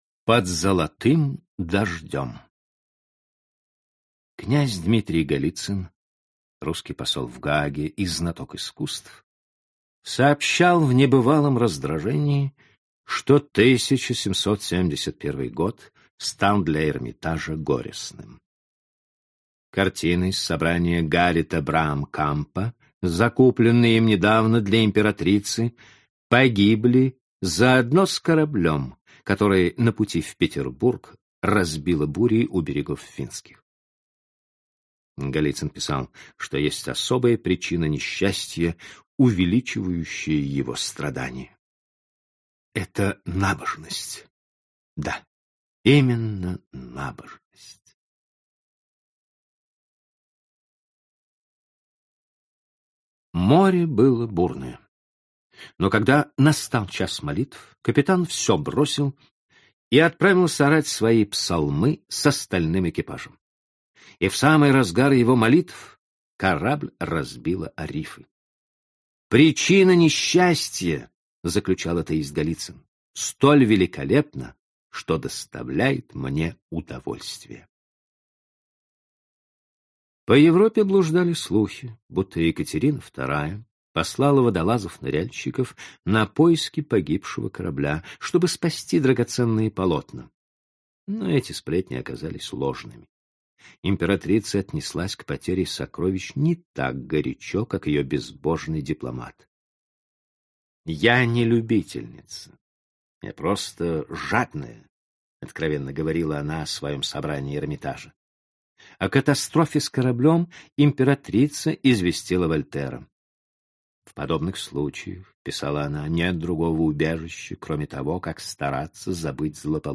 Aудиокнига Под золотым дождем Автор Валентин Пикуль Читает аудиокнигу Сергей Чонишвили.